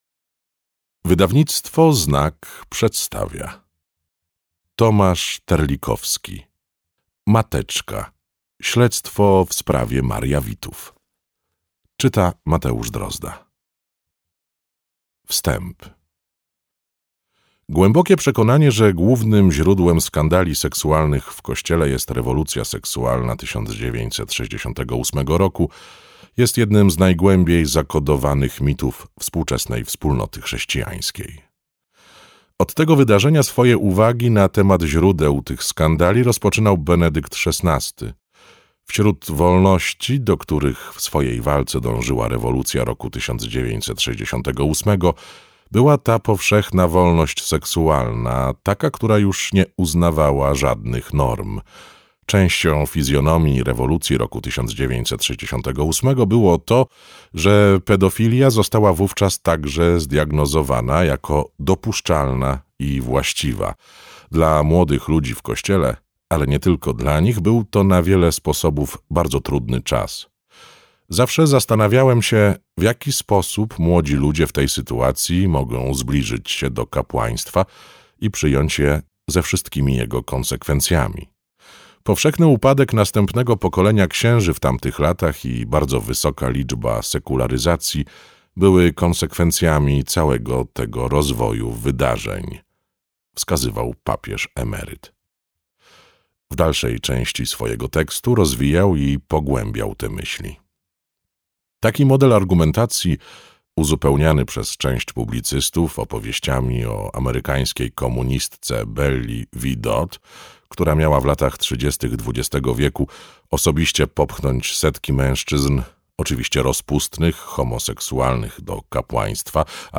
Mateczka. Śledztwo w sprawie mariawitów - Tomasz Terlikowski - audiobook